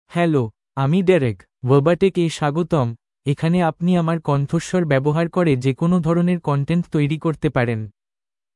MaleBengali (India)
DerekMale Bengali AI voice
Derek is a male AI voice for Bengali (India).
Voice sample
Derek delivers clear pronunciation with authentic India Bengali intonation, making your content sound professionally produced.